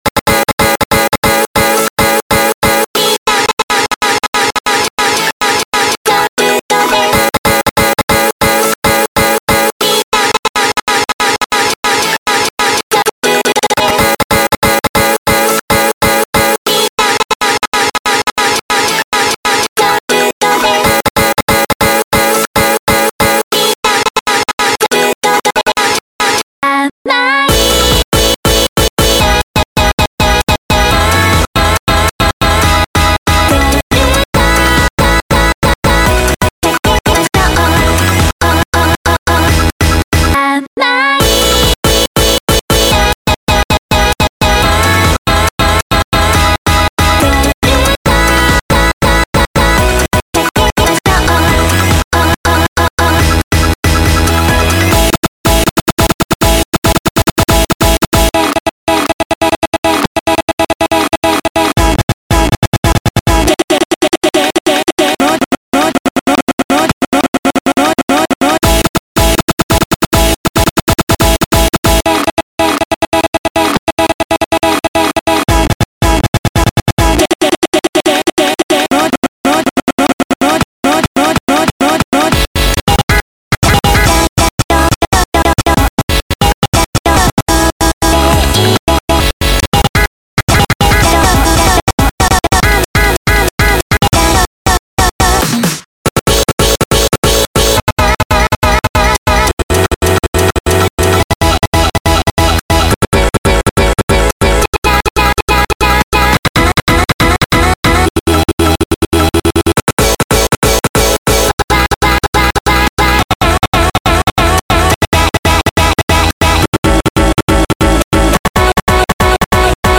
Style : Cut up